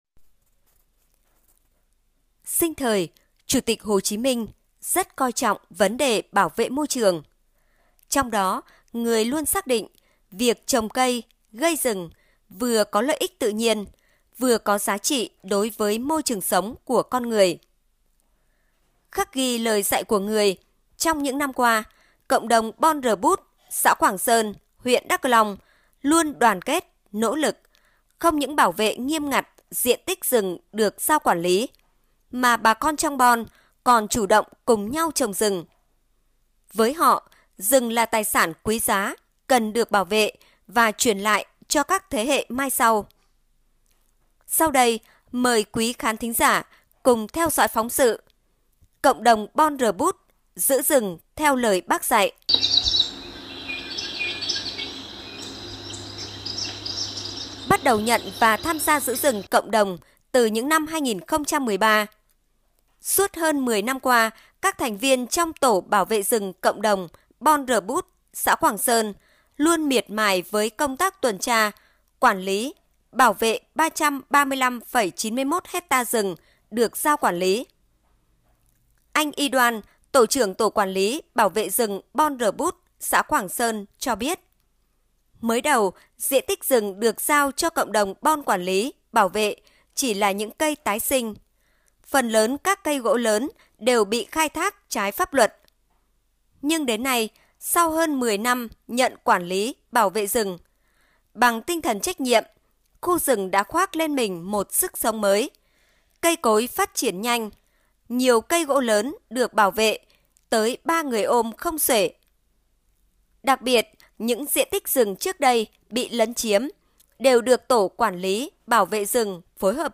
Sau đây mời quý khán thính giả cũng theo dõi phóng sự: “Cộng đồng bon R’Bút giữ rừng theo lời Bác dạy”